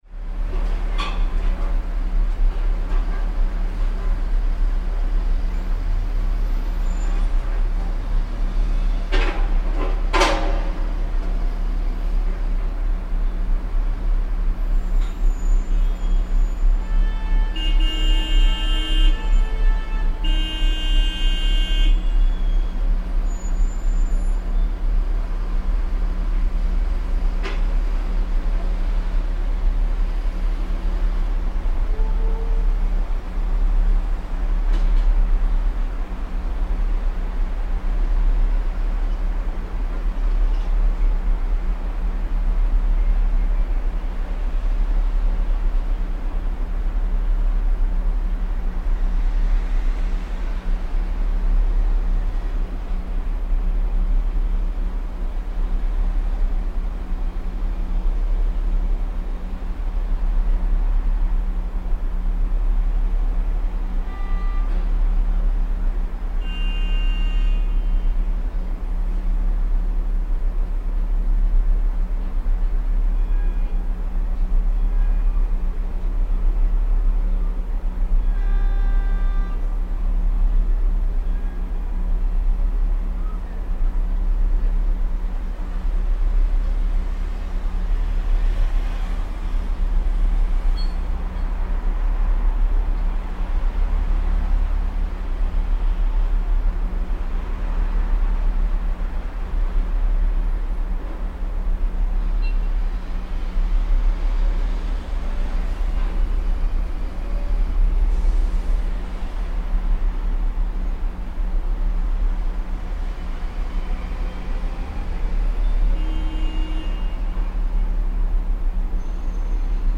here's some London ambiance of builders digging up the road, and a picture to go with it:
Since today seems to be mess up the road day, oh, and disable the traffic lights day, many many many vehicles are upset, as you'll hear in this short clip.